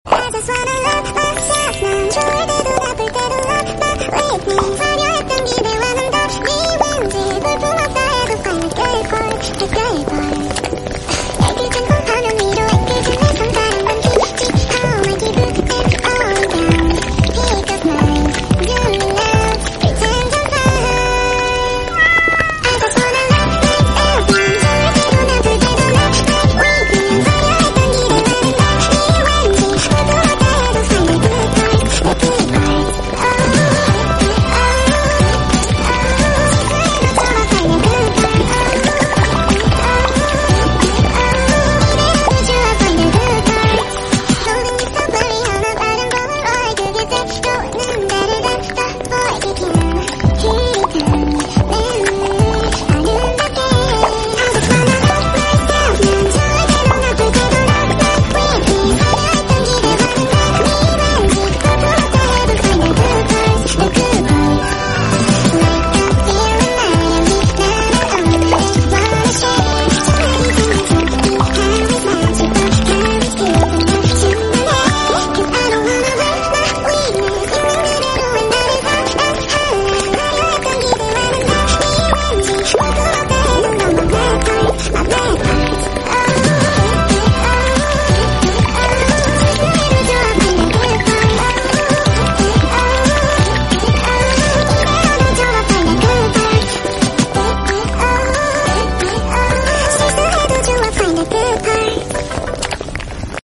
Good Parts Cafe Sound !